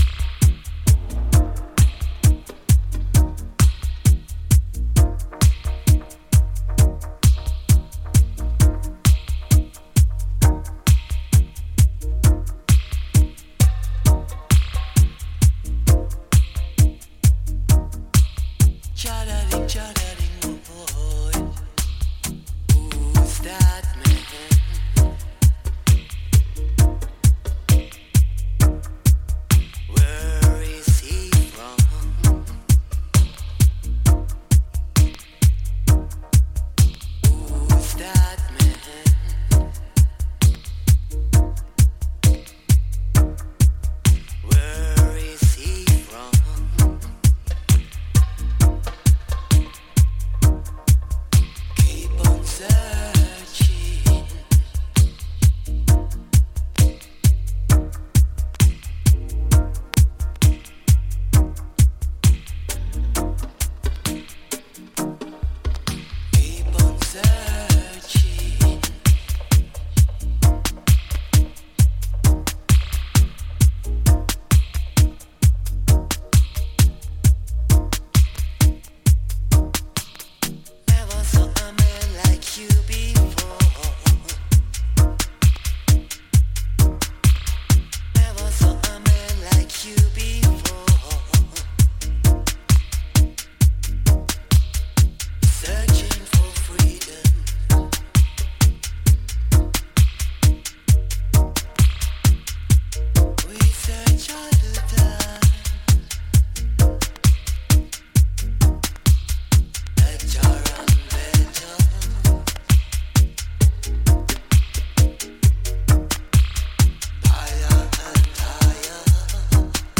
より深いグルーヴで引き込む